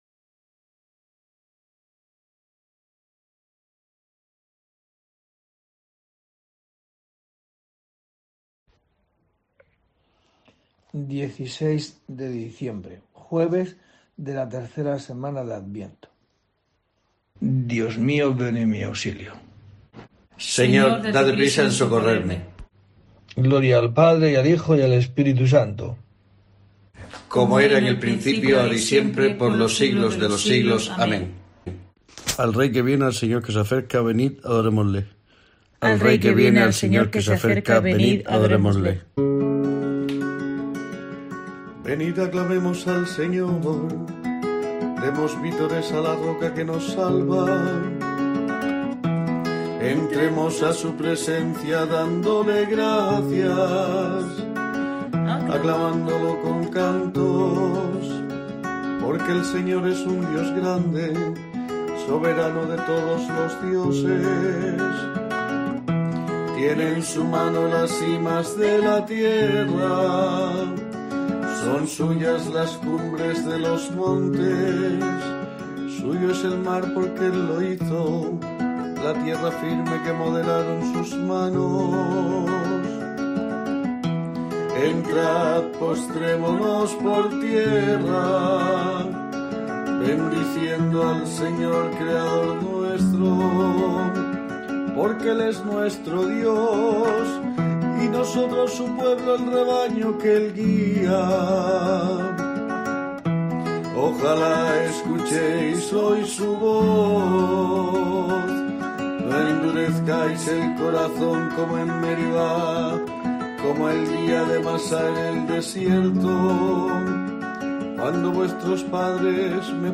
Laudes